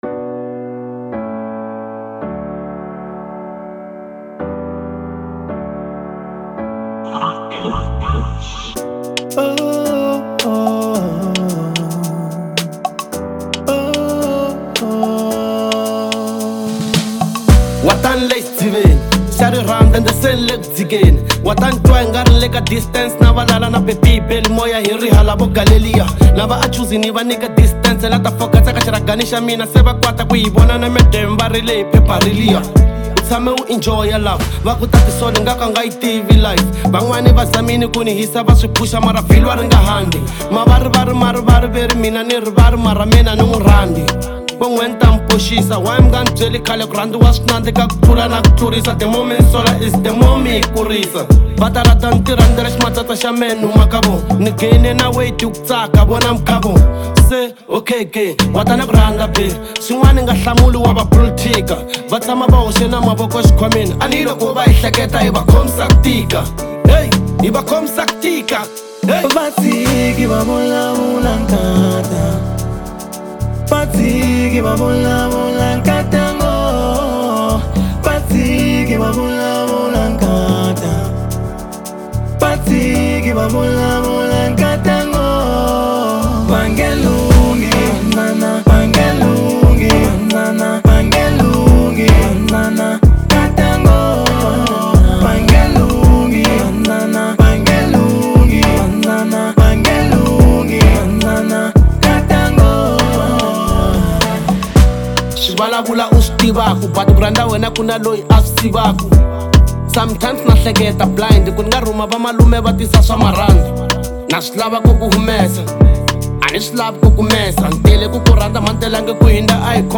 Afro Pop Size